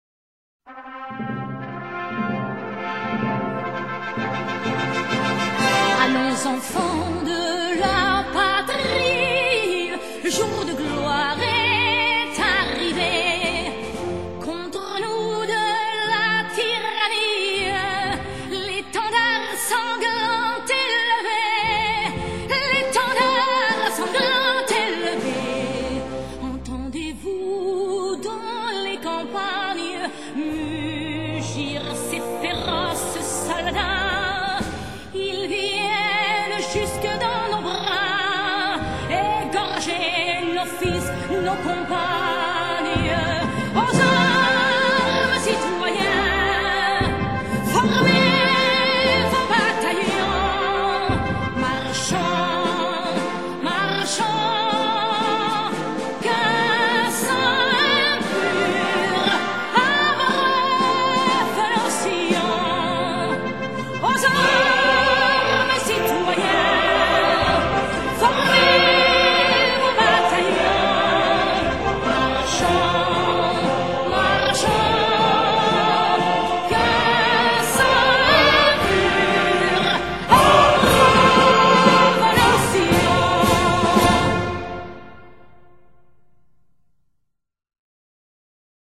Mireille_Mathieu singing_La_Marseillaise_CUT.MP3